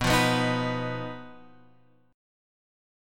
BMb5 chord {7 8 9 8 x 7} chord